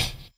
Closet Hat